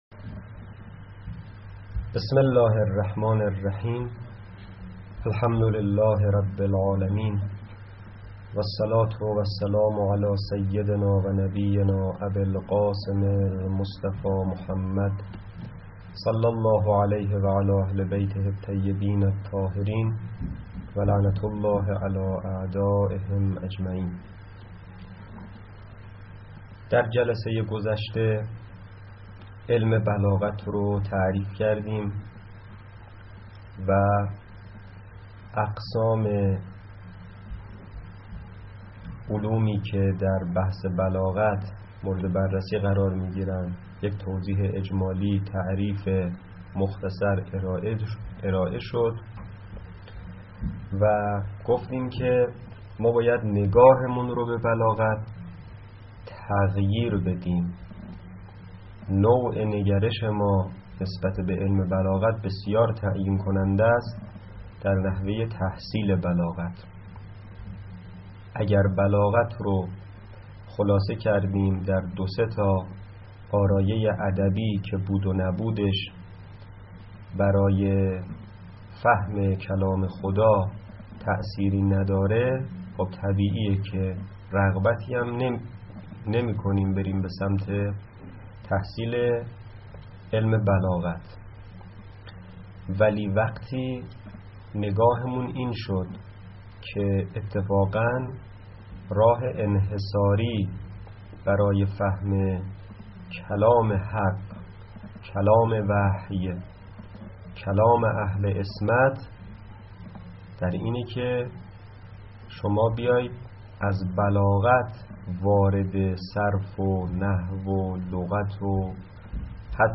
.جلسه دوم تدریس بلاغت